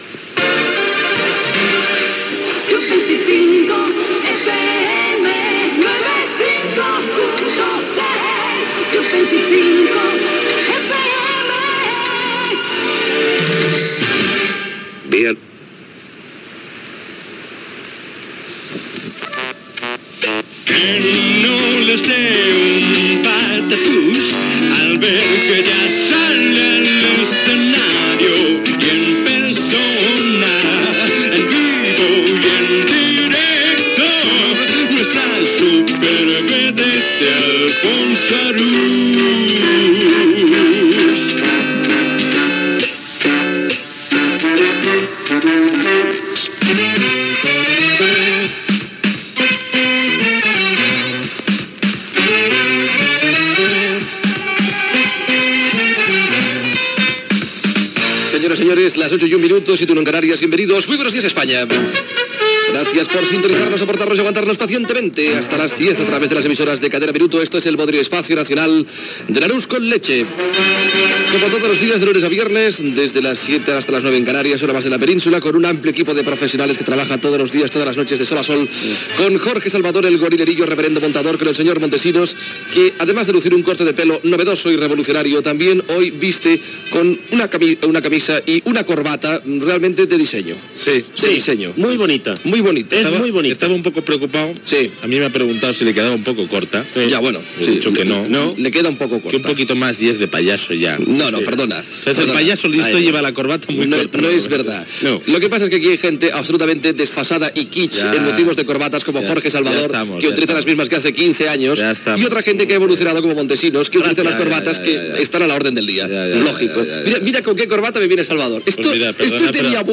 Indicatiu, presentació de l'equip i diàleg sobre les corbates, informació del temps, regal a Javier Martín, robatori del cotxe de Javier Cárdenas, intervenció de Carmen Sevilla, José María Ruiz Mateos, Jesús Gil i José Manuel Lara (imitacions)
Entreteniment
FM